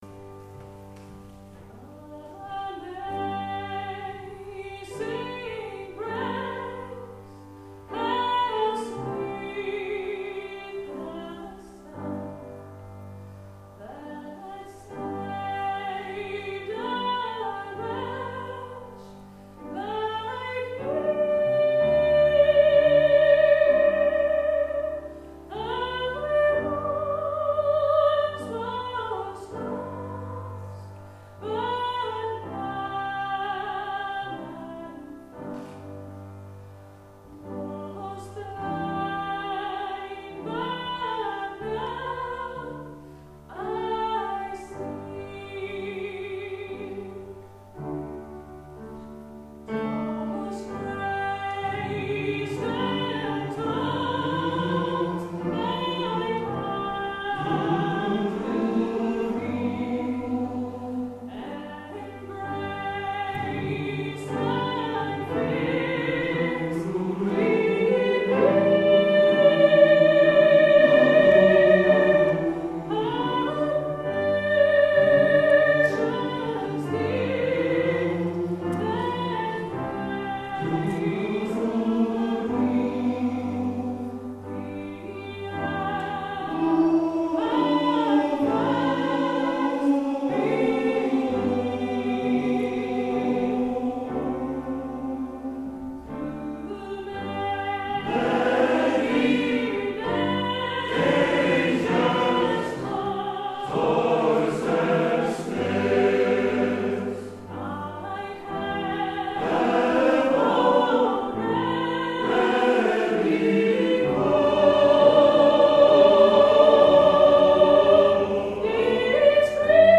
Konsertopptak
Julekonsert i Rosendal søndag 13. des 2009